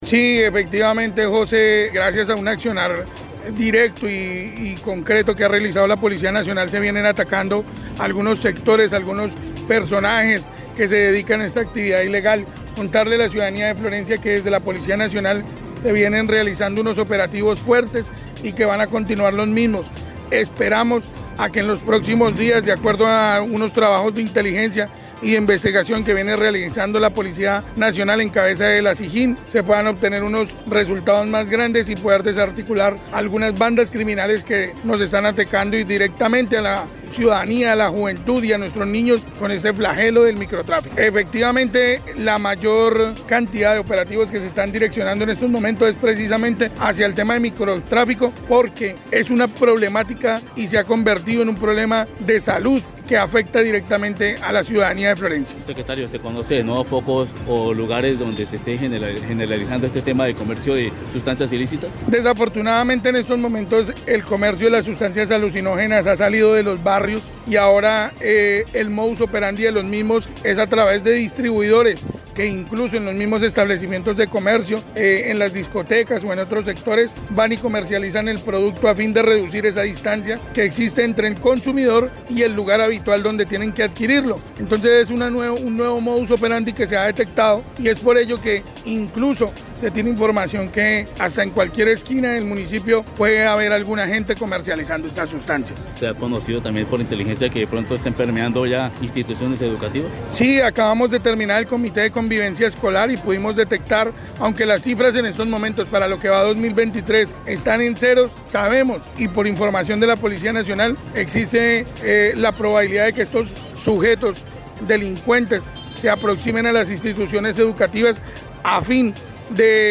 Jaime Becerra, secretario de gobierno del municipio de Florencia, explicó que lo anterior ha quedado en evidencia tras los recientes operativos en contra de estas organizaciones criminales, donde se ha conocido que los denominados “distribuidores” salen de las mal llamadas “ollas” para llegar directamente al consumidor en las calles.